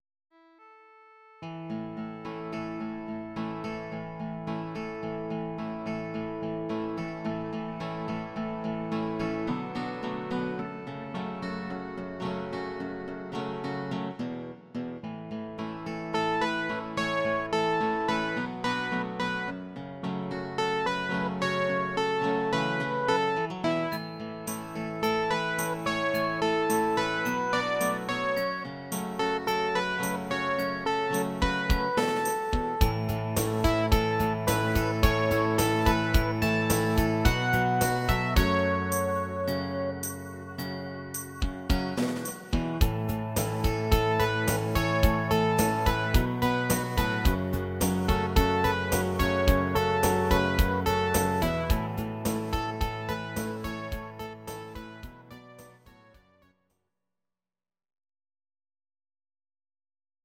These are MP3 versions of our MIDI file catalogue.
Your-Mix: Rock (2958)